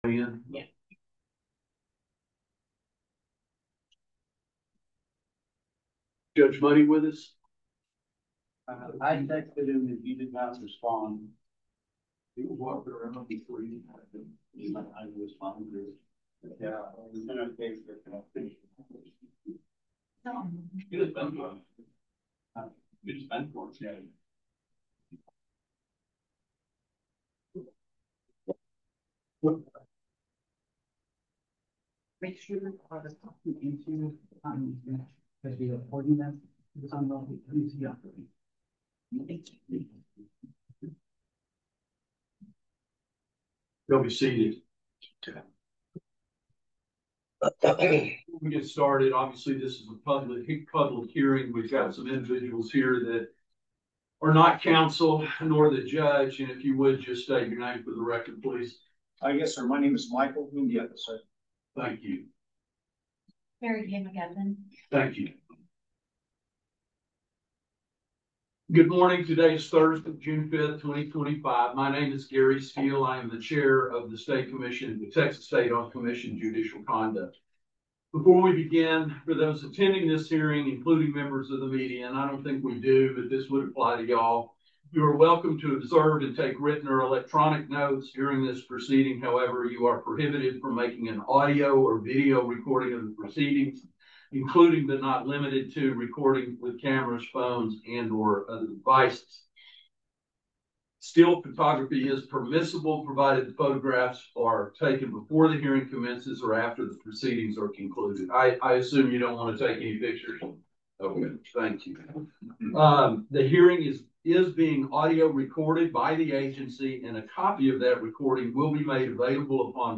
Post-Suspension Hearings | State Commission on Judicial Conduct